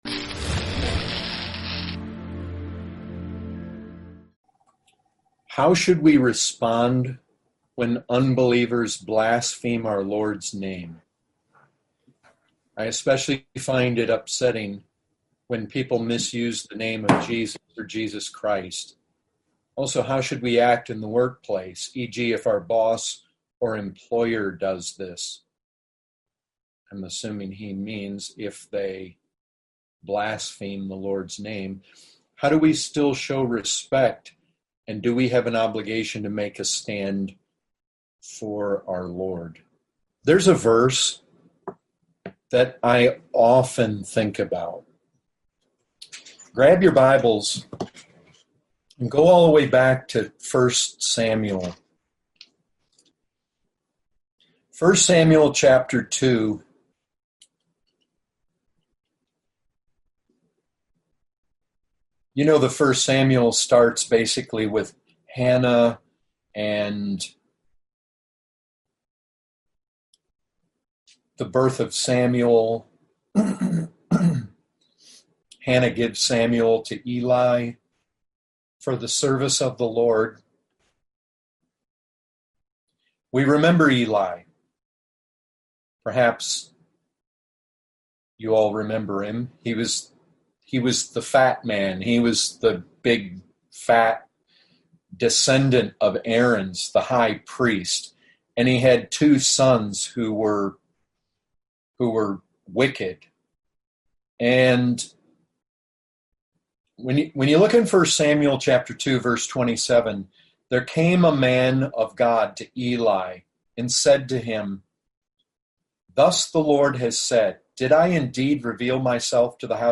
Category: Questions & Answers